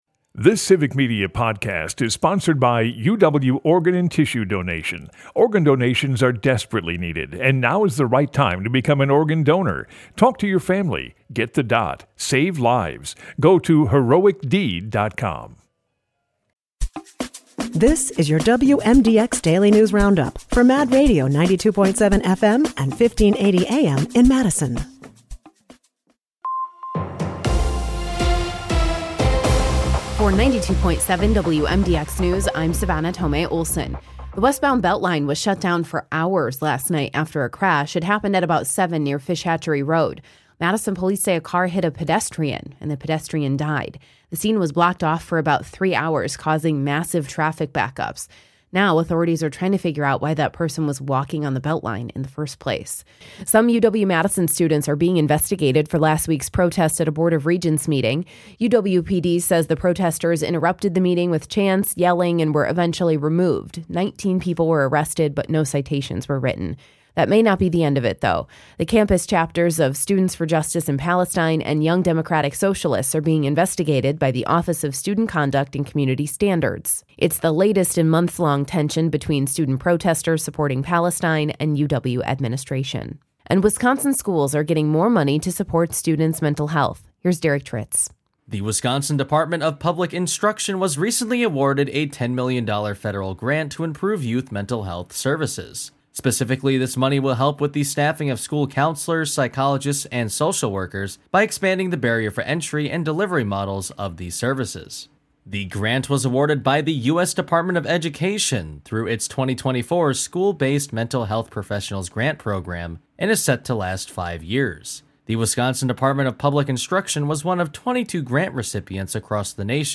wmdx news